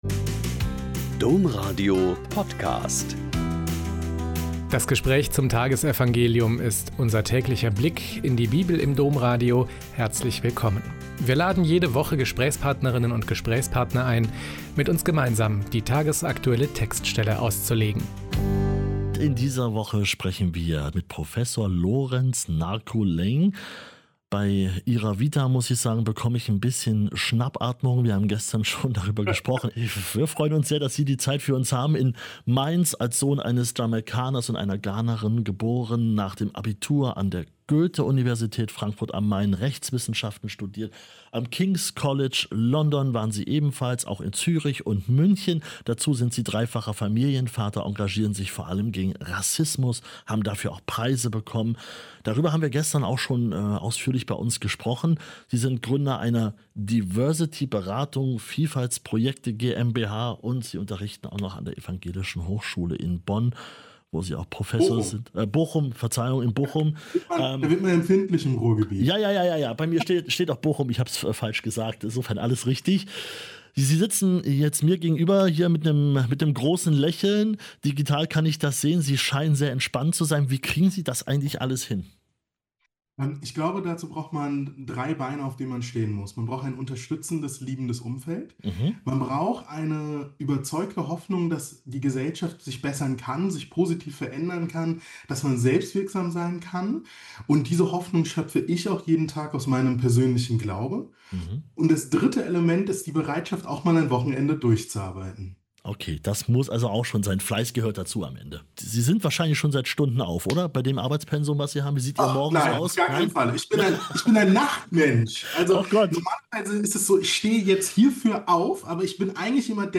Lk 4,31-37 - Gespräch